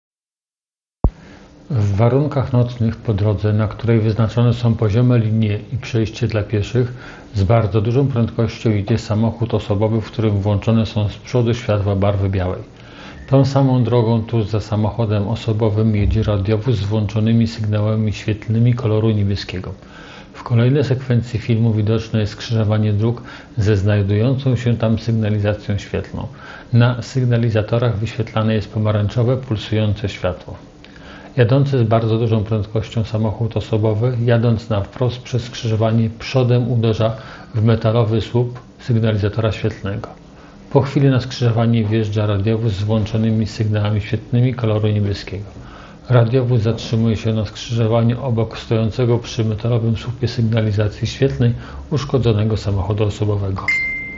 Nagranie audio Audiodeskrypcja do filmu Z promilami, na sądowym zakazie uciekał policjantom i „wylądował” na słupie